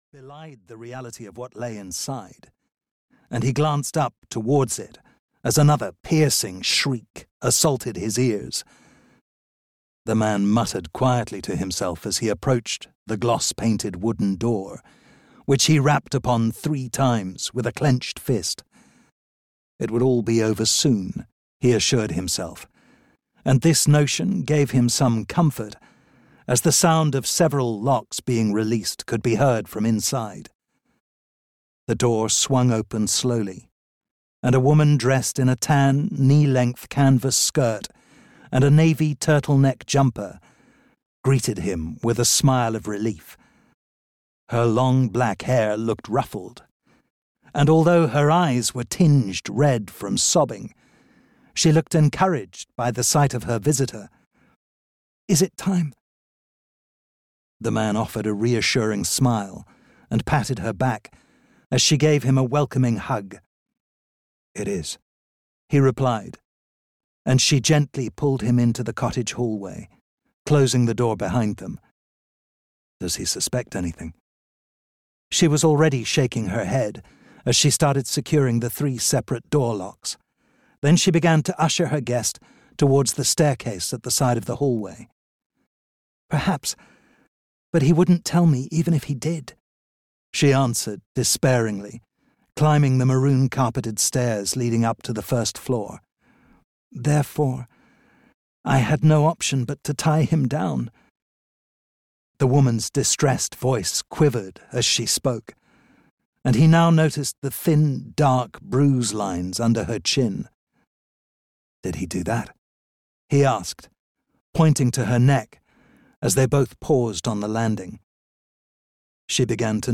The Dark Temple (EN) audiokniha
Ukázka z knihy